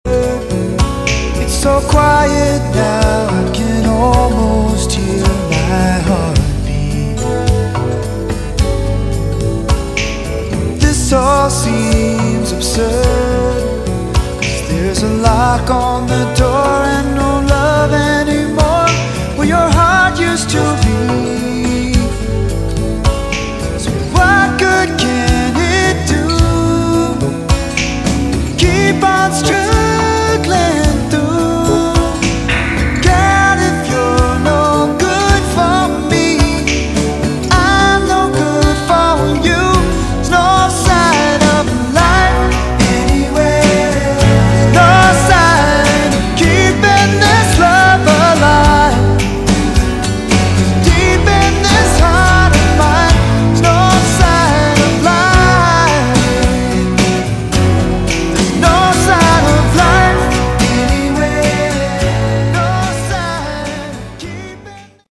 Category: Westcoast AOR